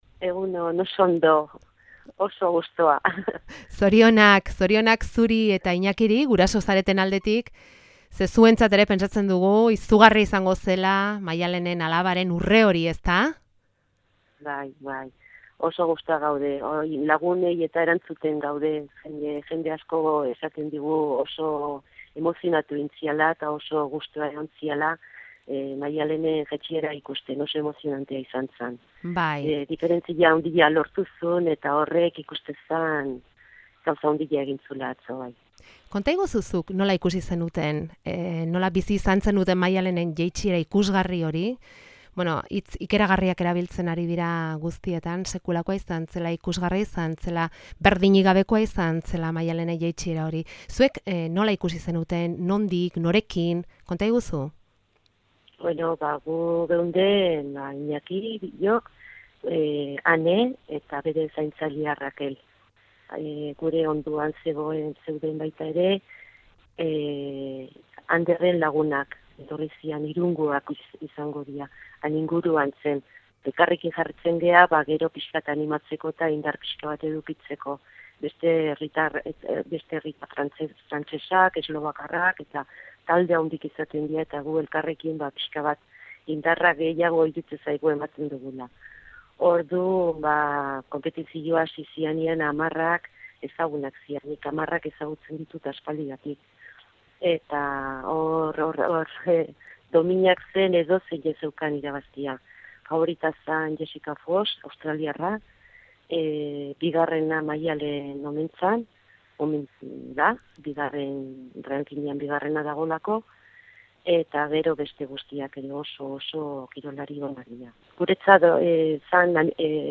Audioa: Arnasarik hartu gabe jarraitu zuten Rion, Maialenen jetsiera eta urrea alabarentzat zela jakitean, eromena. Emozio handiz hitz egin du.